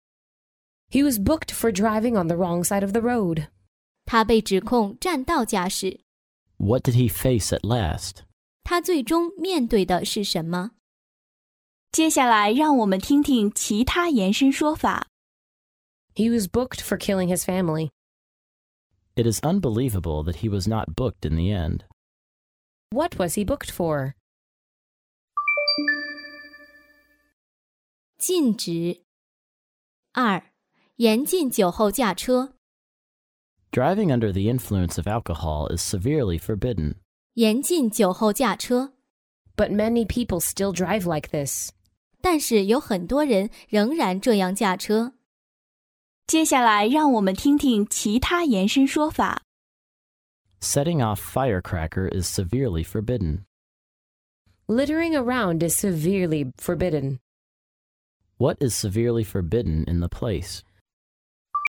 真人发音的朗读版帮助网友熟读熟记，在工作中举一反三，游刃有余。